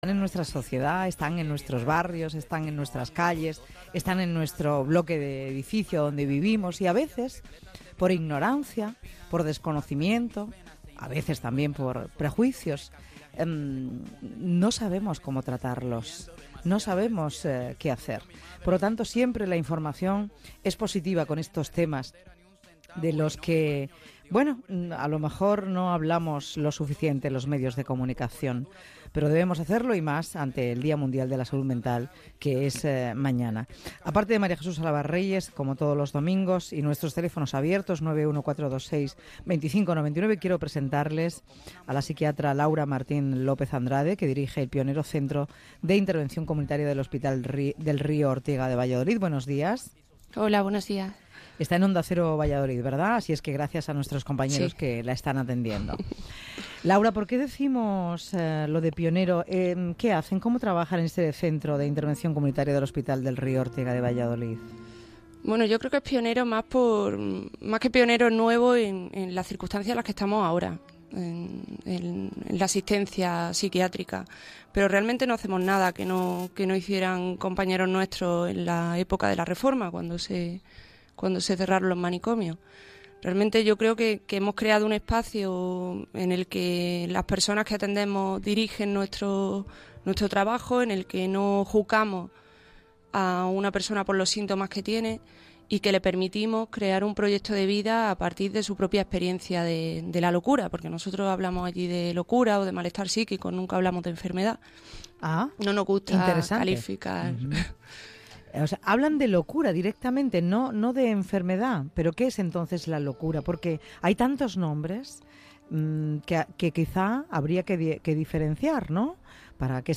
Nos sumamos al día mundial de la Salud Mental, que se conmemora el lunes 10 de octubre, con un corte de audio del Programa de Onde Cero «Te doy mi palabra» emitido hoy domingo.